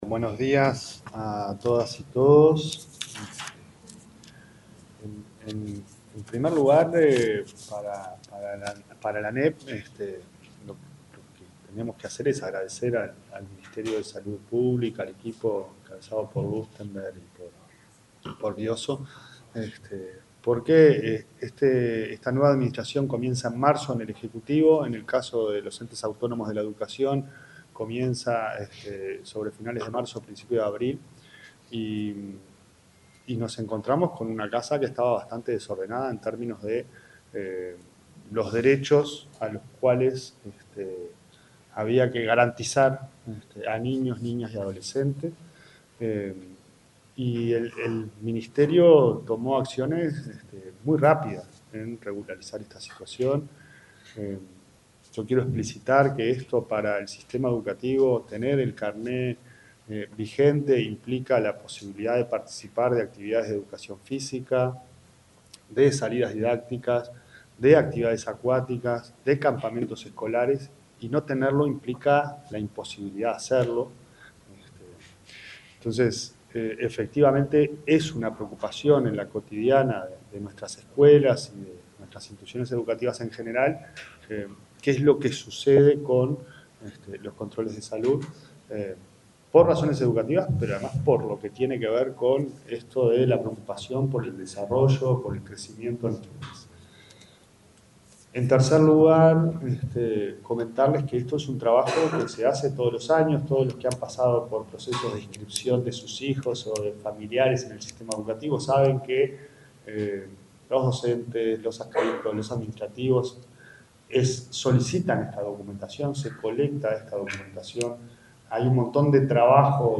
Palabras de la ministra de Salud, Cristina Lustemberg, y el presidente de la ANEP, Pablo Caggiani
Durante la presentación de un informe sobre el control en salud de niños y adolescentes, expusieron la ministra de Salud Pública, Cristina Lustemberg,